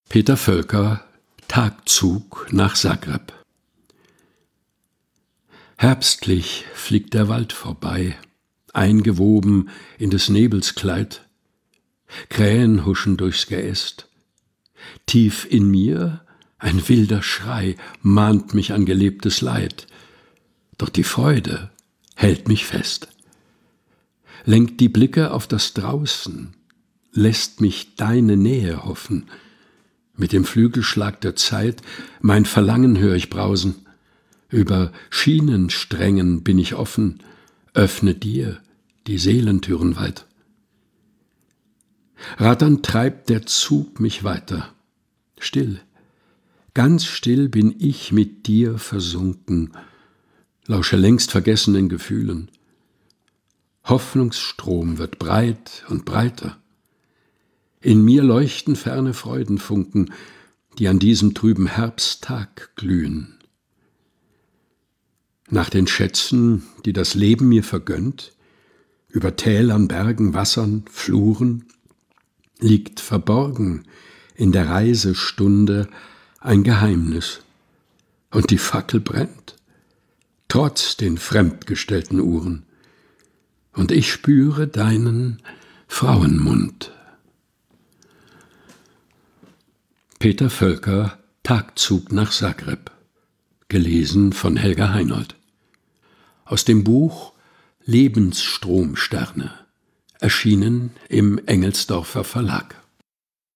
eigens zwischen Bücherregalen eingerichteten, improvisierten Studio